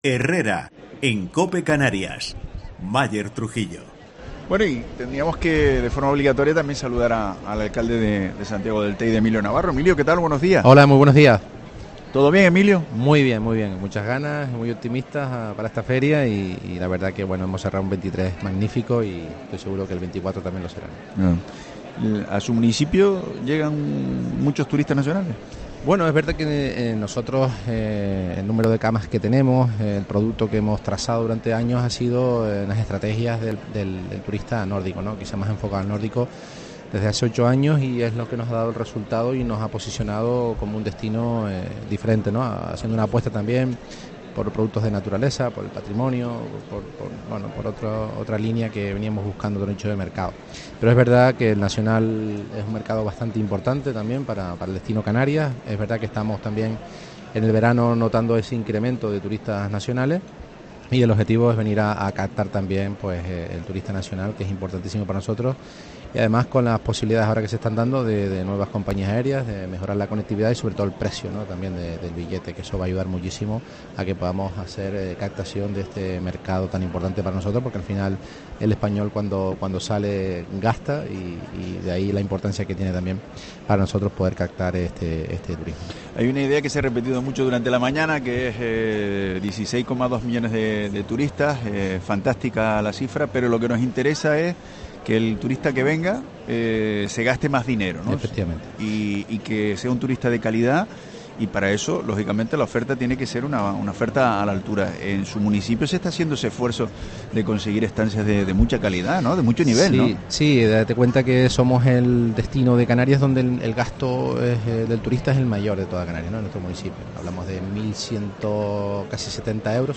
Entrevista a Emilio Navarro, alcalde de Santiago del Teide, en FITUR